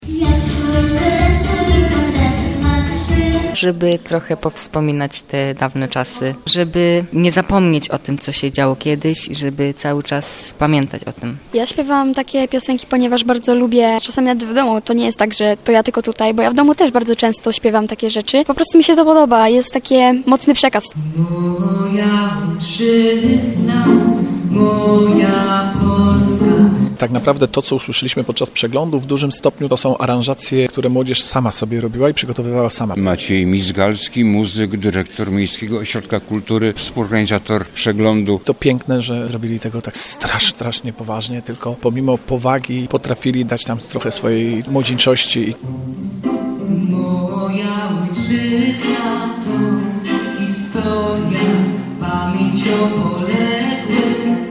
Na scenie MOK-u wystąpili soliści, zespoły oraz chóry.